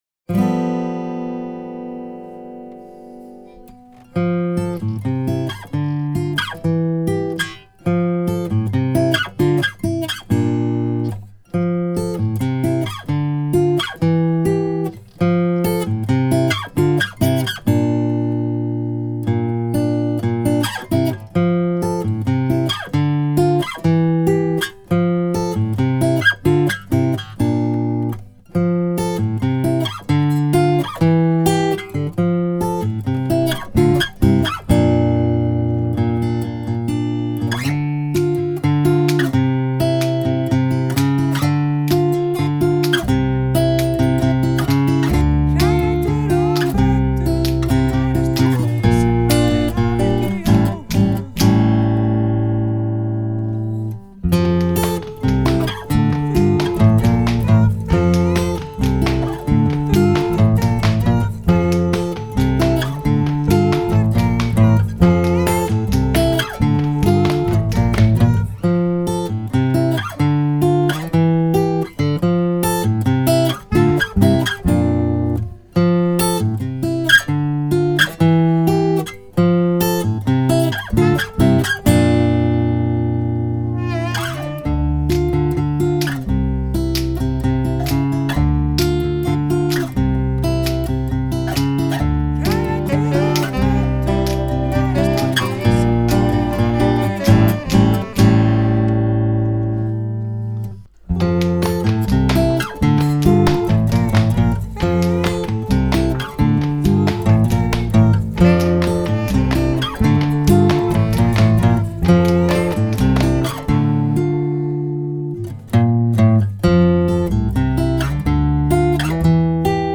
Karaoki útgáfa